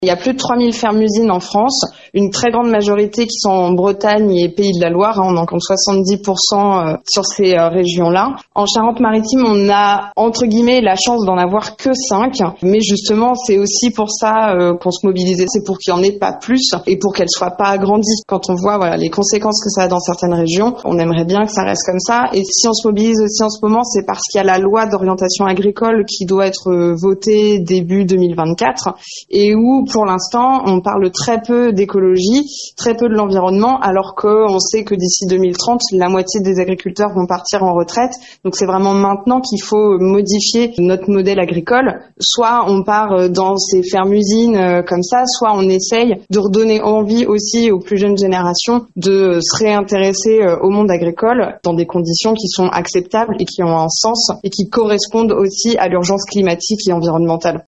Mobilisation des militants de Greenpeace hier matin devant la préfecture de La Rochelle.